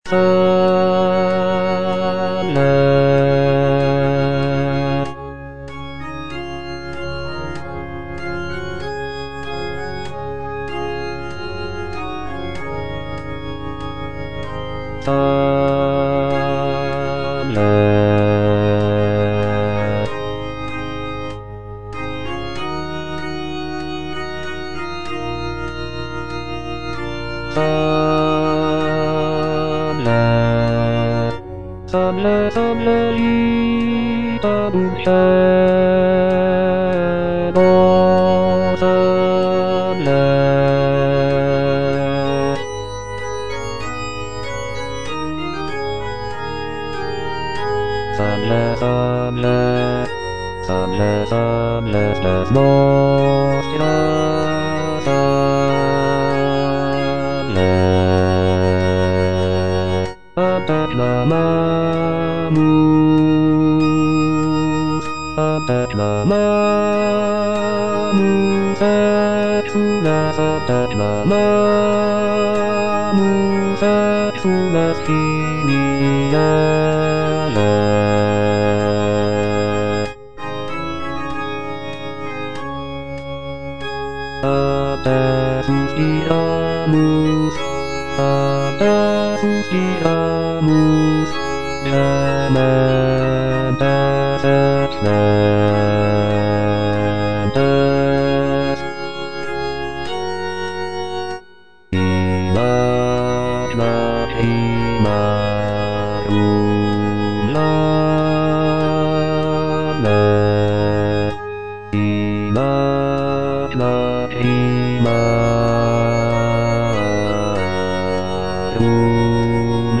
A = 415 Hz
Bass (Voice with metronome) Ads stop
sacred choral work